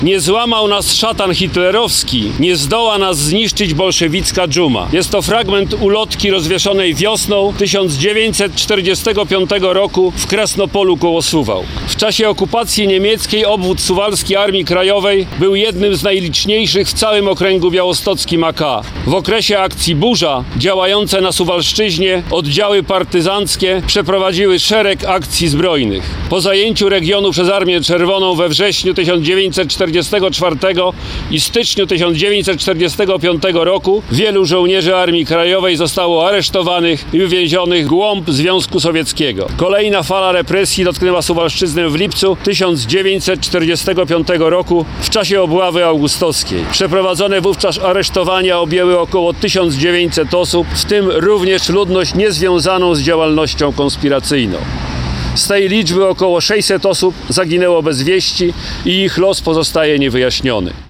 W sobotę (01.03) członkowie Grupy Rekonstrukcji Historycznej „Garnizon Suwałki”, wspólnie ze Związek Piłsudczyków RP Ziemi Suwalskiej oraz Związkiem Strzeleckim „Strzelec„ w Suwałkach wystawili wartę i zapalili znicze przed tablicą na budynku byłej siedziby Powiatowego Urzędu Bezpieczeństwa Publicznego w Suwałkach oraz pomnikiem 41. Suwalskiego Pułku Piechoty AK.